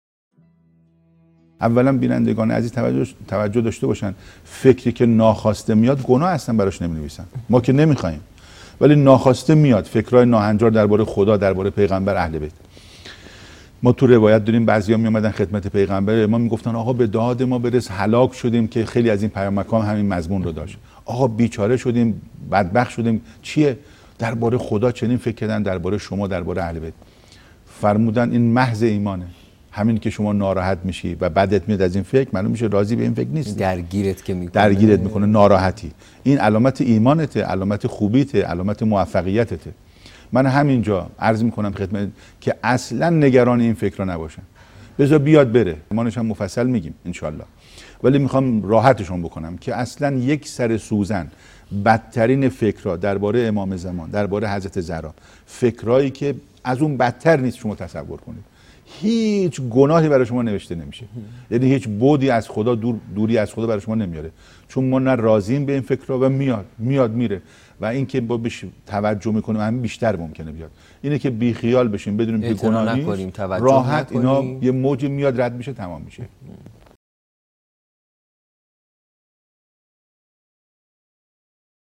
سخنرانی | افکار ناخواسته، گناه محسوب نمی‌شوند